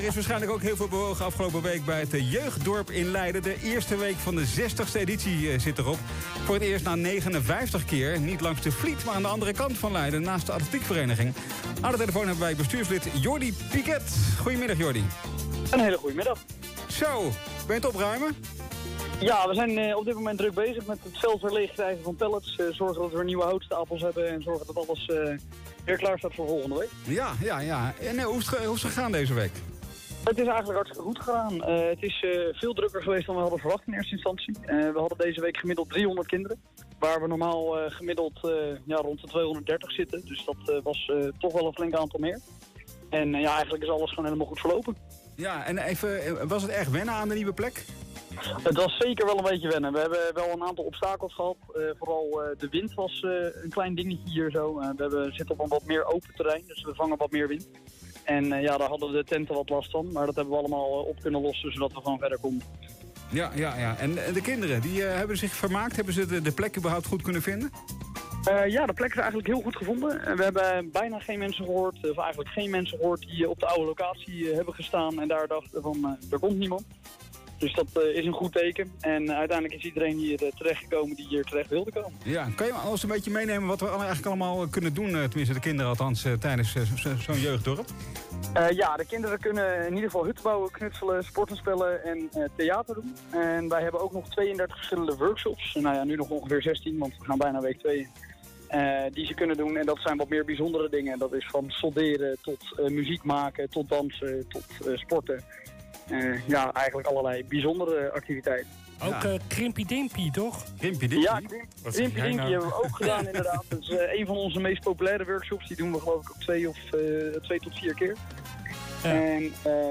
De Centraal+ VrijMiBo is wekelijks op vrijdag van 17.00 uur tot 19.00 uur live te volgen via radio, televisie en online.
Interview Leiden Maatschappij Nieuws
Telefonisch-Jeugddorp-Leiden.wav